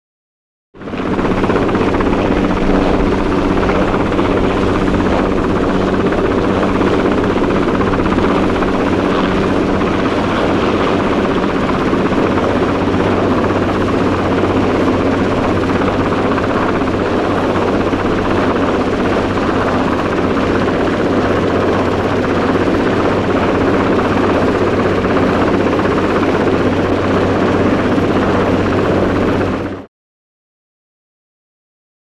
Helicopter; Interior; Wessex Mk5 Helicopter Exterior In Flight.